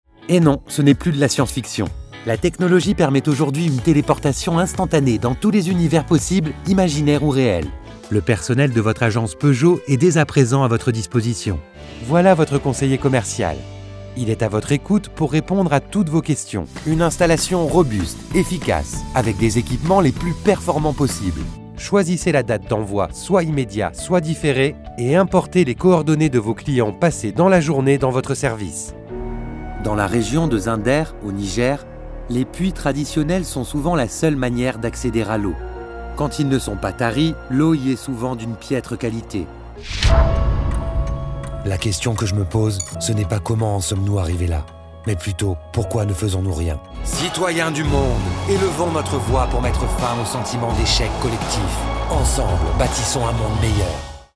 广告合集-磁性稳重